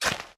sounds / item / hoe / till4.ogg
till4.ogg